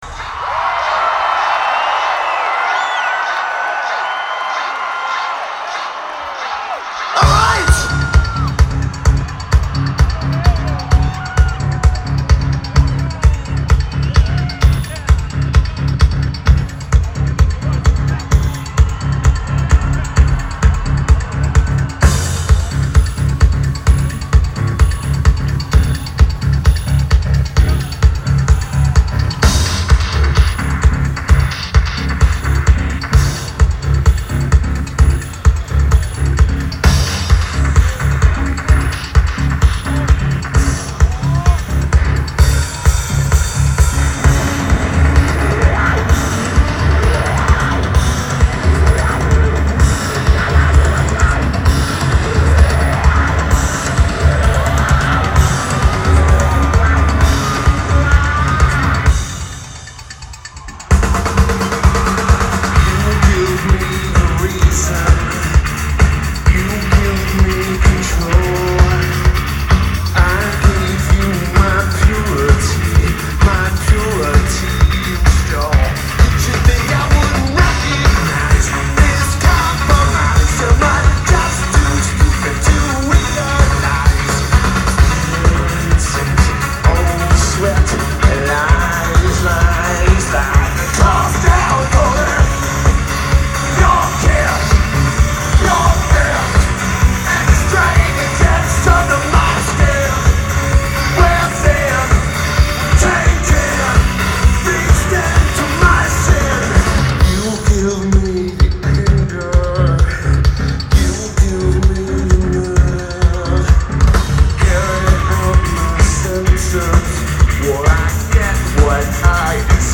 Lineage: Audio - AUD (DPA 4060 + Sony NH900) Rating